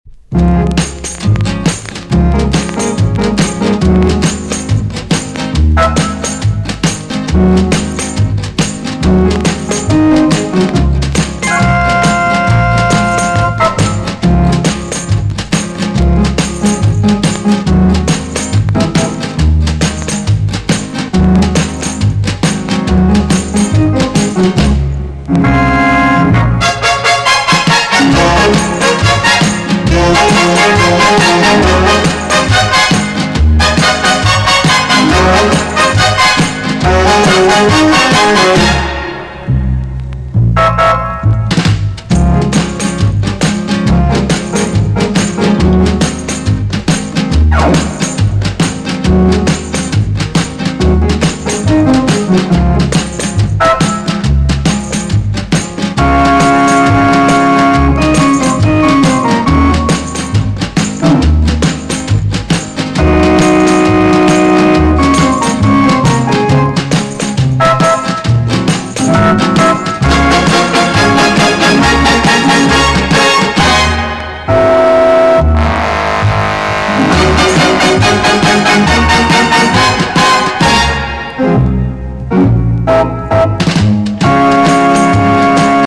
EX- 音はキレイです。
1958 , WICKED MAMBO JAZZ TUNE!!